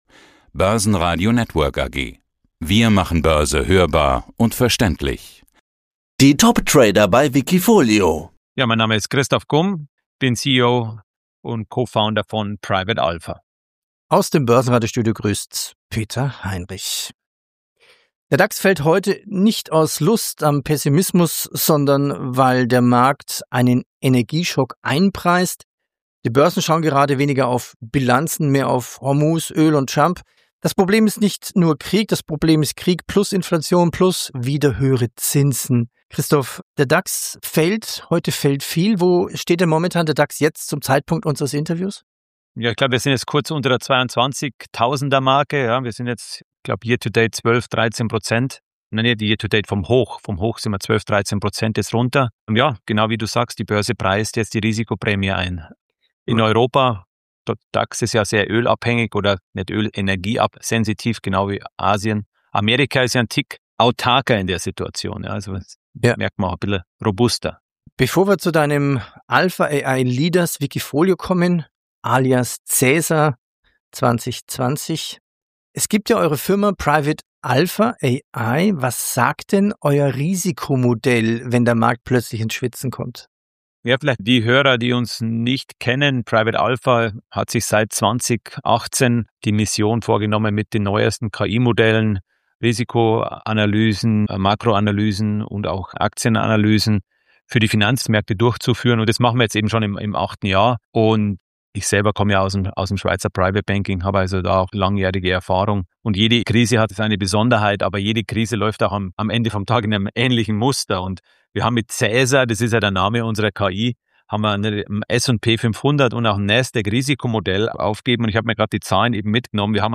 ~ Die besten wikifolio-Trader im Börsenradio Interview Podcast